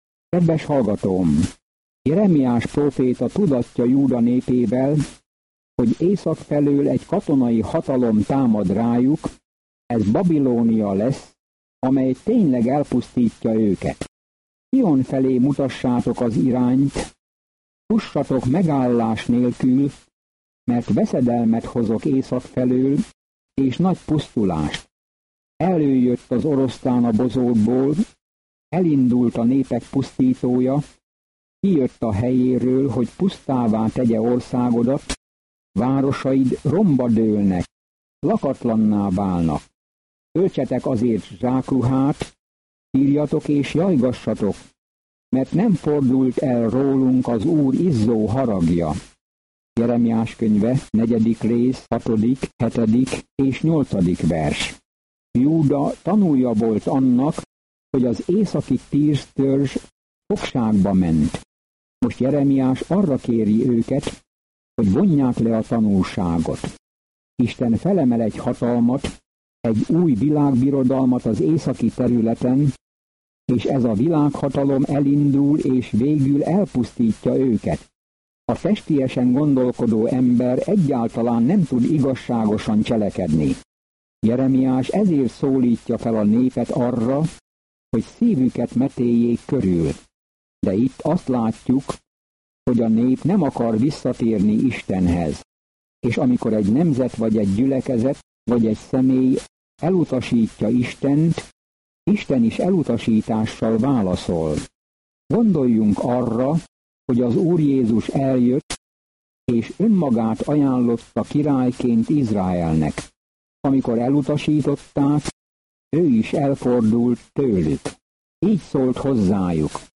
Szentírás Jeremiás 4:6-31 Jeremiás 7:1-8 Nap 5 Olvasóterv elkezdése Nap 7 A tervről Isten Jeremiást, a gyengéd embert választotta ki, hogy kemény üzenetet közöljön, de az emberek nem fogadják jól az üzenetet. Naponta utazz Jeremiáson, miközben hallgatod a hangos tanulmányt, és olvasol válogatott verseket Isten szavából.